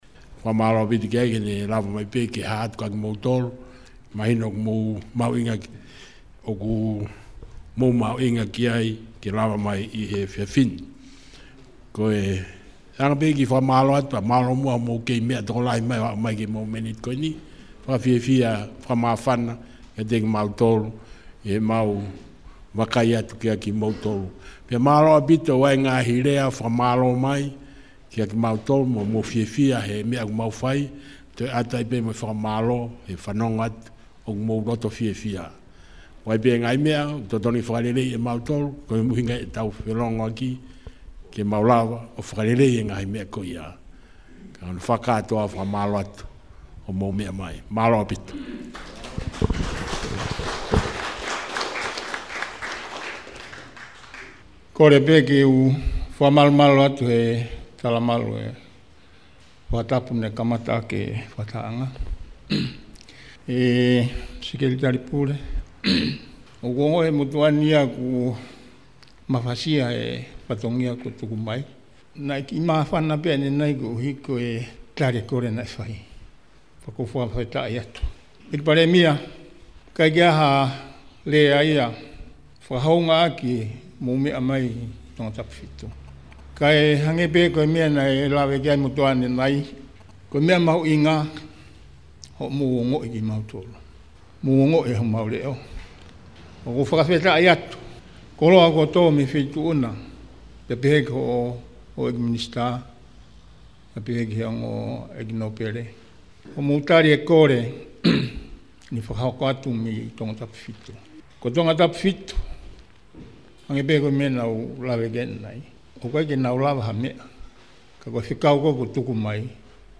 Kiliki ‘i he ‘ōtiō ‘i ‘olunga’ ke ke fanongo ki he fakataha ‘a e ‘Eiki Palēmia’ mo e kāinga Tongatapu 7.